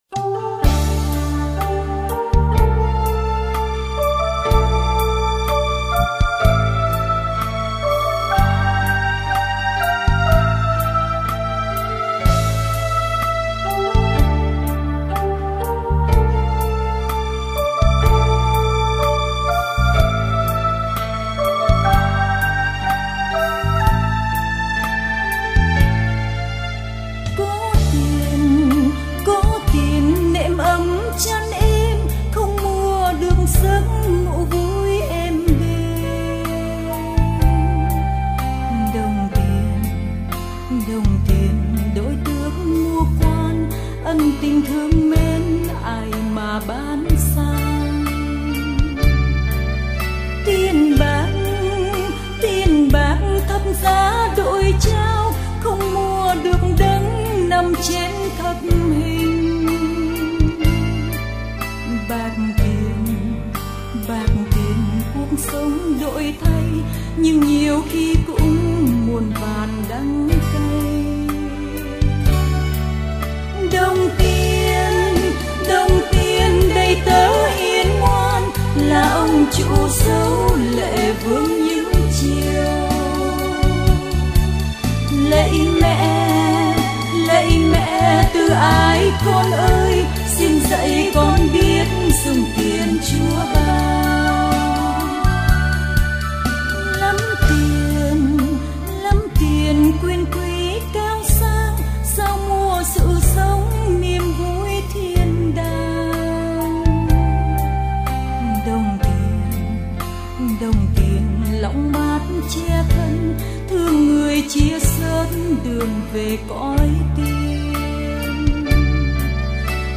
Nghe nhạc thánh ca. Bài hát được phát từ Website